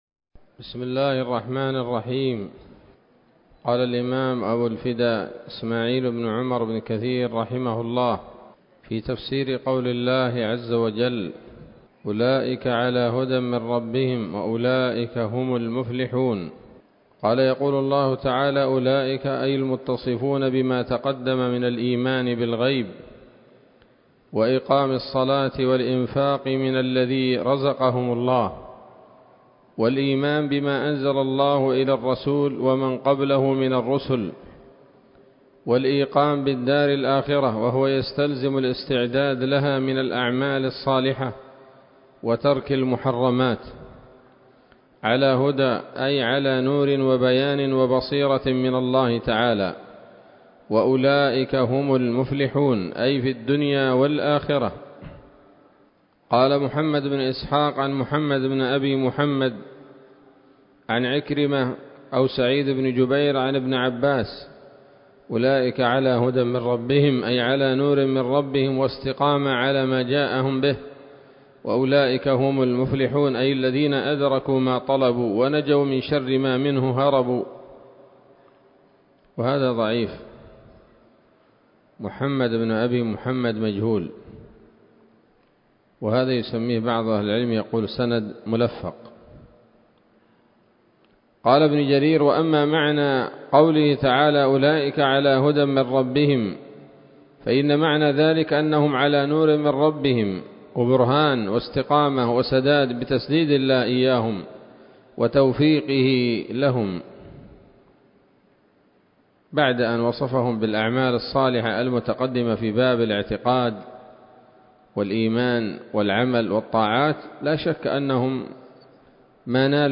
الدرس الثاني عشر من سورة البقرة من تفسير ابن كثير رحمه الله تعالى